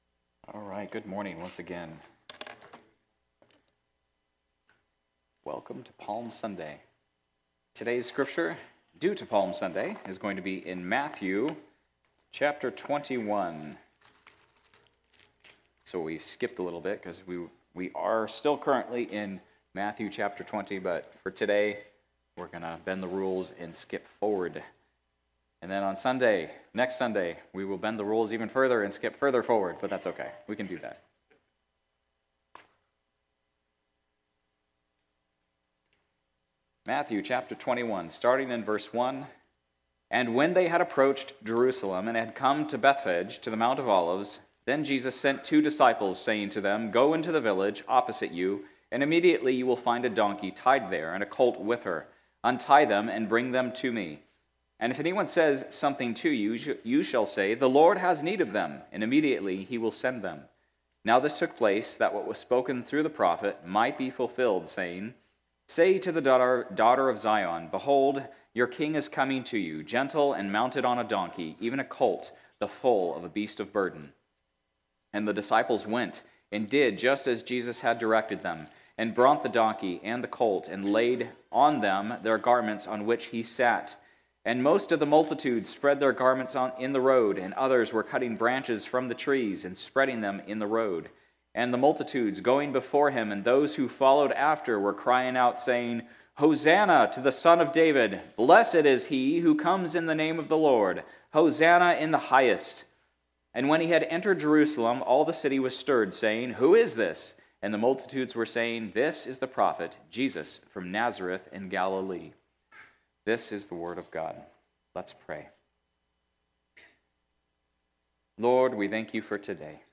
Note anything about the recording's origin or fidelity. Sermons from Cornerstone Bible Church: Ridgecrest, CA